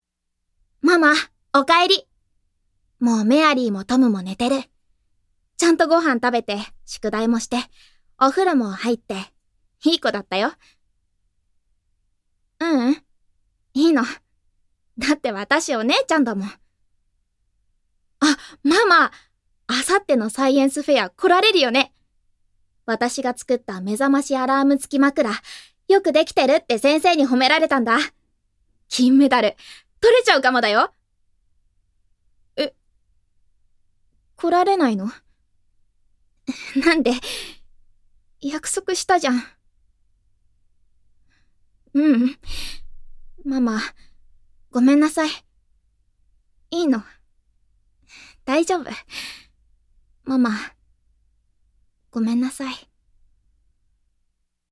◆女の子◆